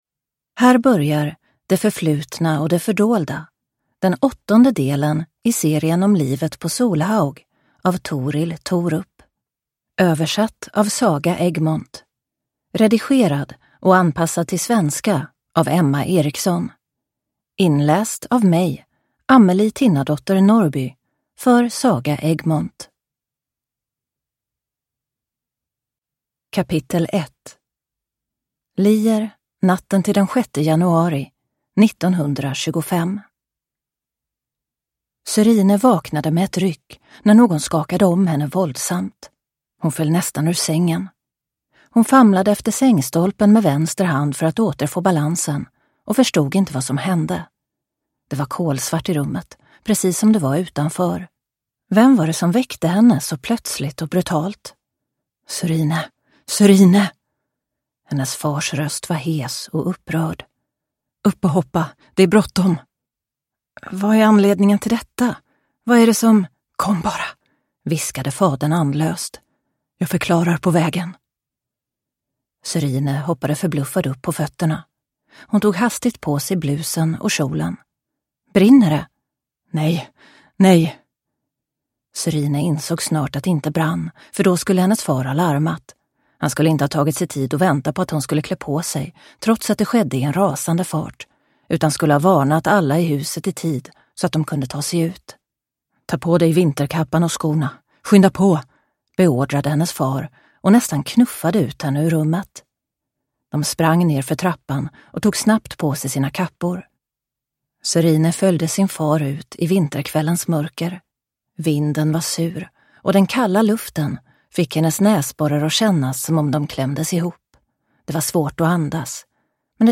Det förflutna och det fördolda (ljudbok) av Torill Thorup